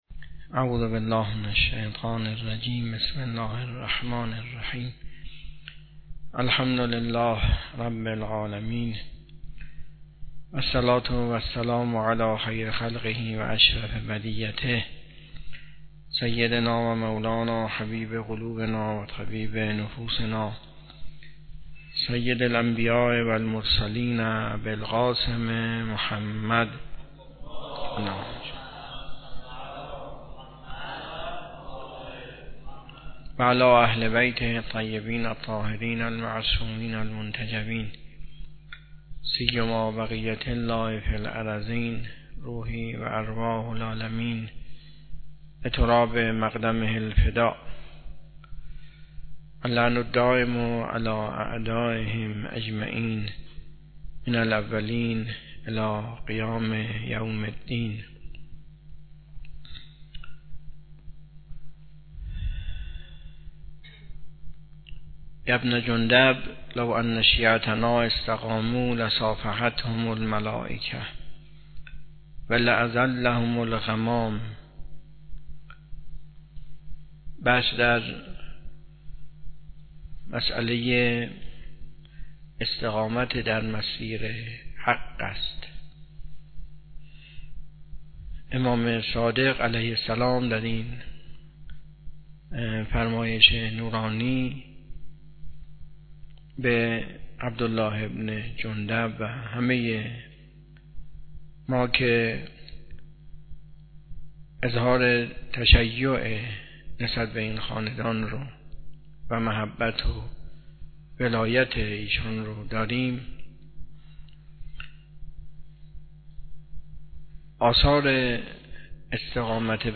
درس اخلاق
حوزه علمیه معیر تهران